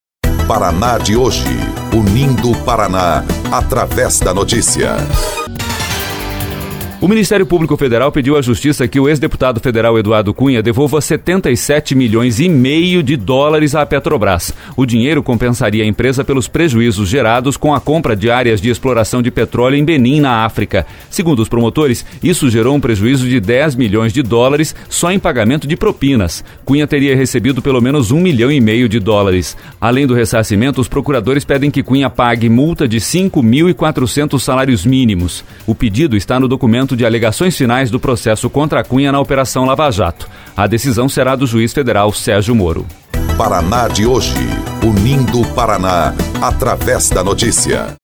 BOLETIM – MPF quer que Eduardo Cunha devolva US$ 77,5 mi à Petrobrás